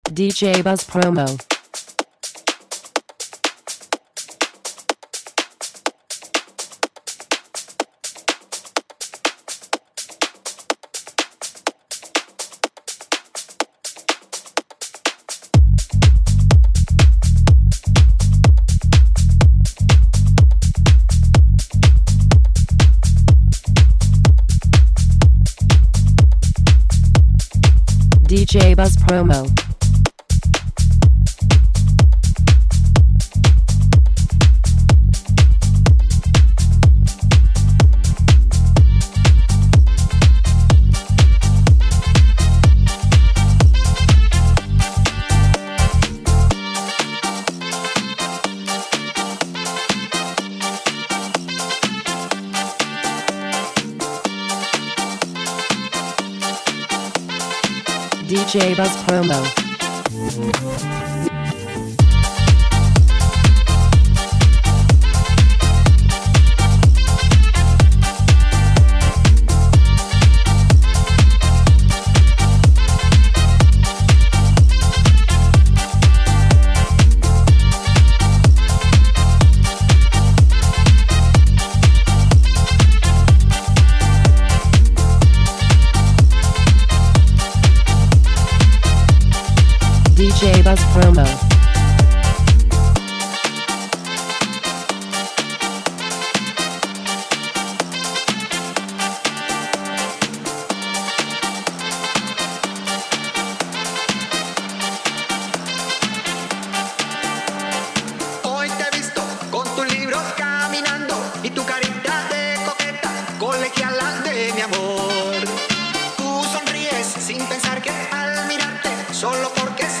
Tropical Flavoured House Music!
Latin-infused club and dance ready ear worm